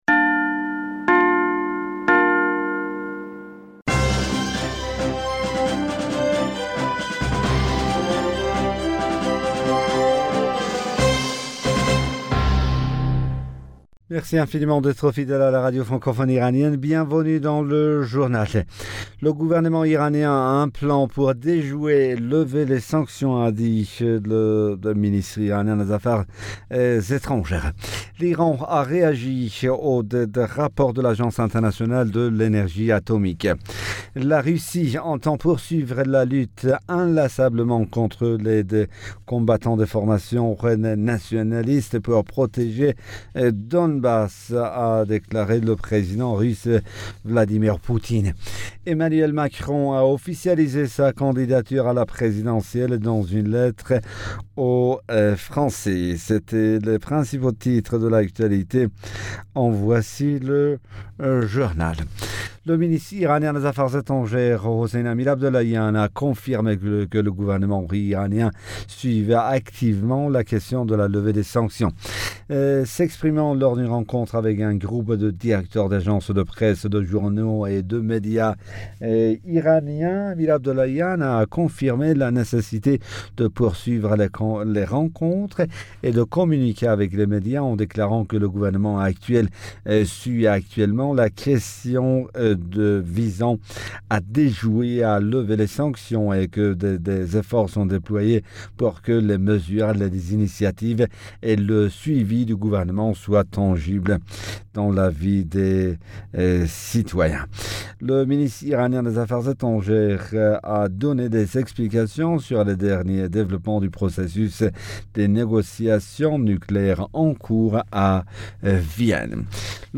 Bulletin d'information Du 04 Mars 2022